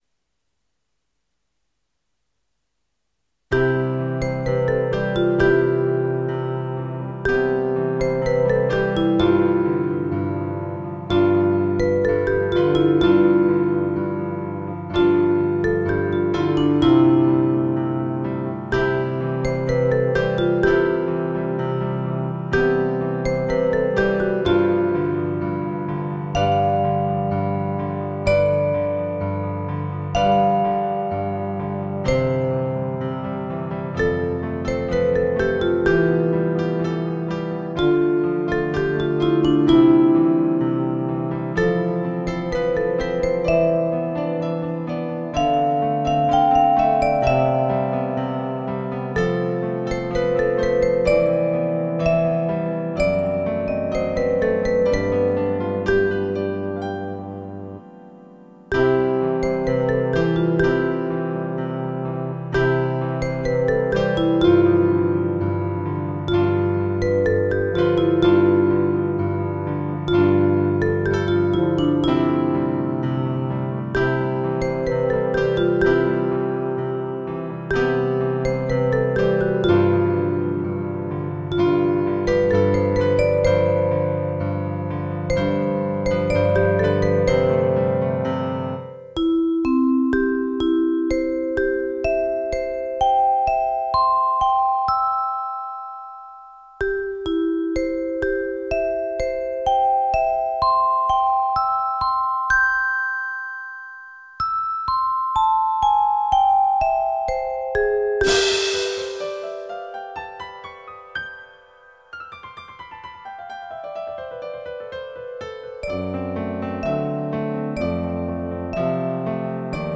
Piano & Instrumental for Children